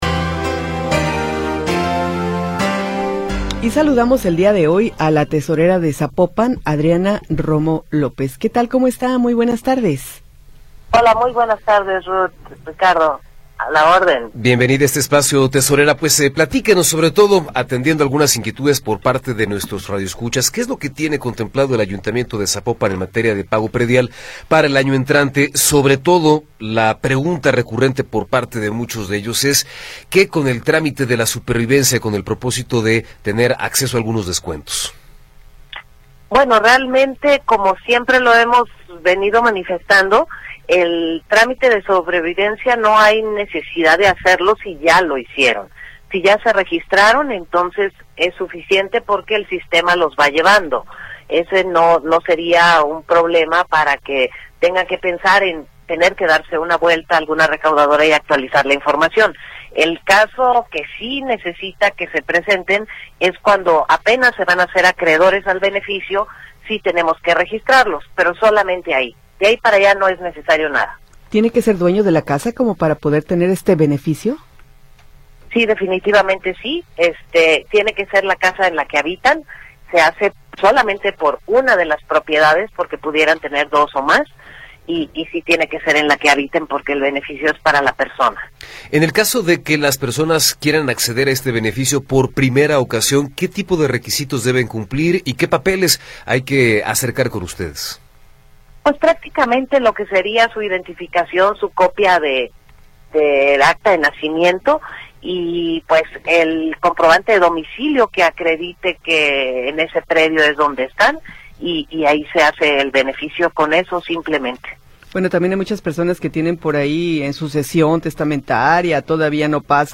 Entrevista con Adriana Romo López